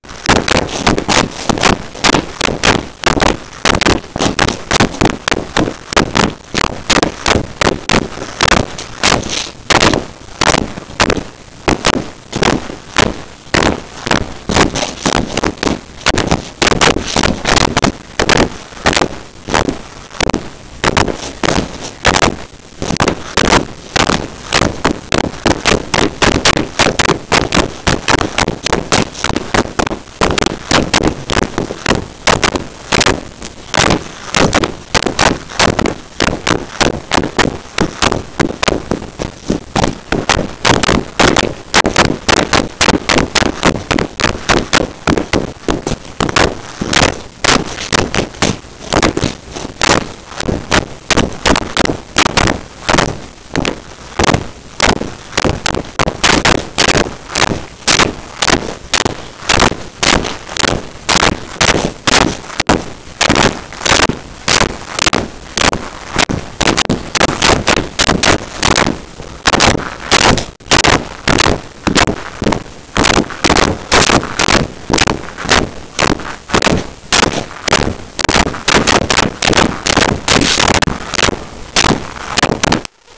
Footsteps Hard Surface
Footsteps Hard Surface.wav